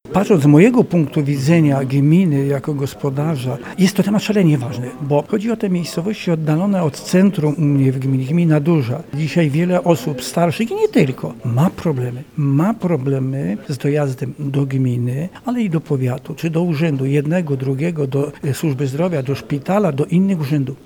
Mówi burmistrz Radomyśla Wielkiego, Józef Rybiński.